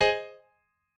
admin-leaf-alice-in-misanthrope/piano34_6_015.ogg at main